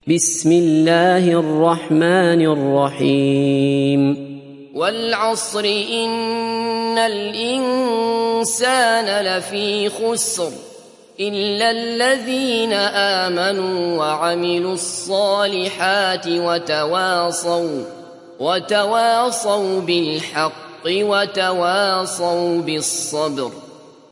تحميل سورة العصر mp3 بصوت عبد الله بصفر برواية حفص عن عاصم, تحميل استماع القرآن الكريم على الجوال mp3 كاملا بروابط مباشرة وسريعة